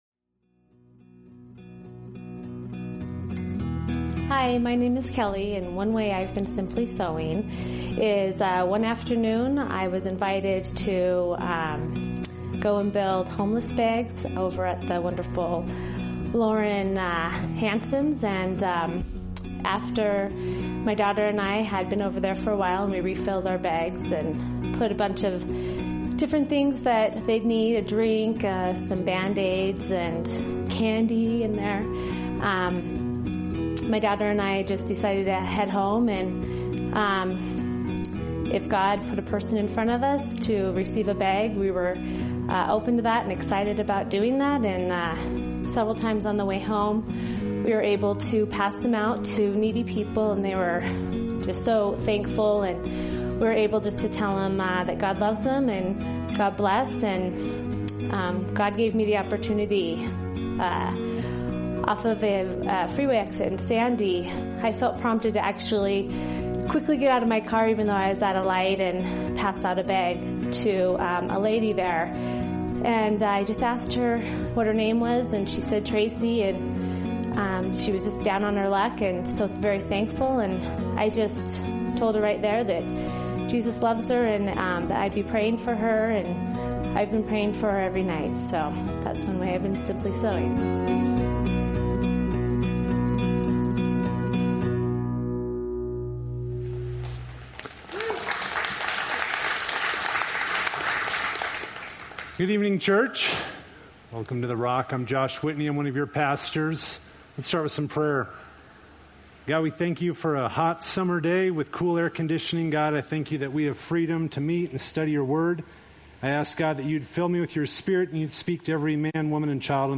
A message from the series "Healthy Living."